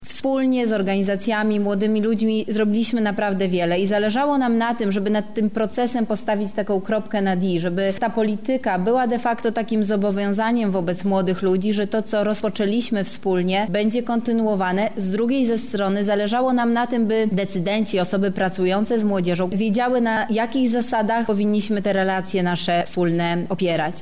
• mówi Beata Stepaniuk- Kuśmierzak, Zastępczyni Prezydenta Lublina ds. Kultury, Sportu i Partycypacji